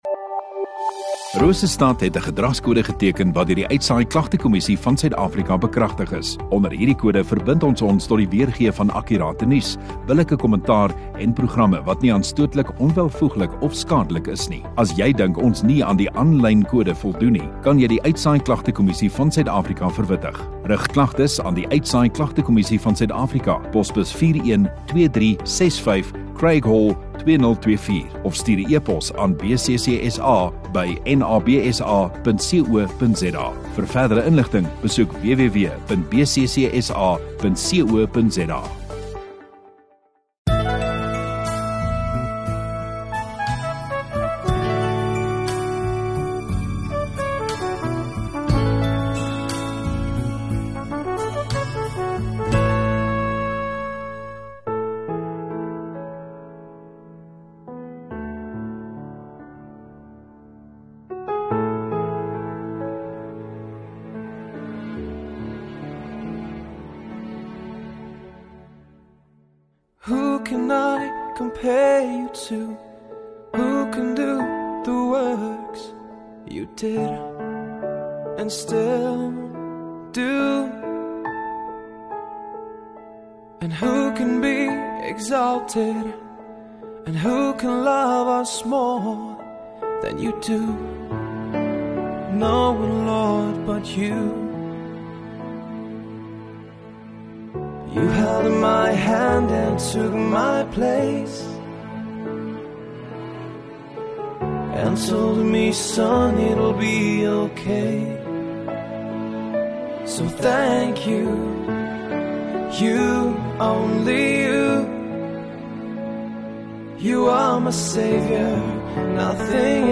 3 Aug Sondagaand Erediens